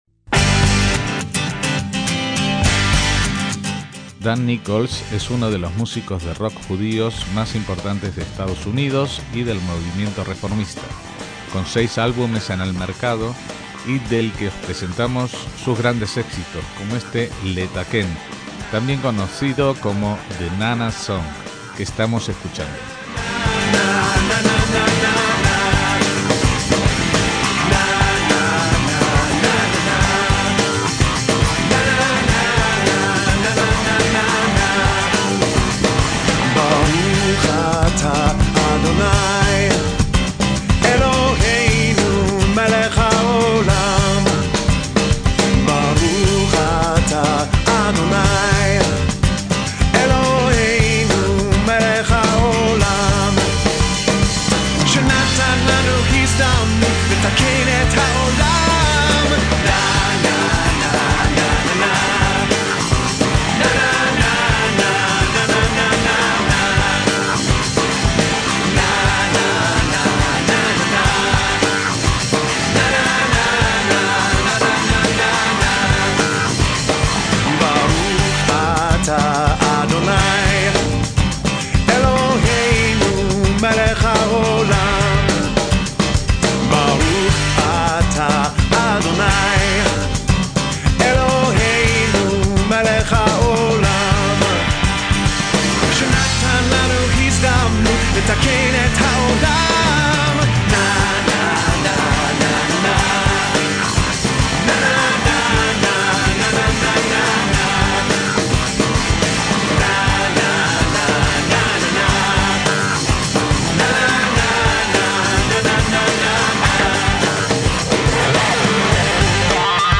músicos judíos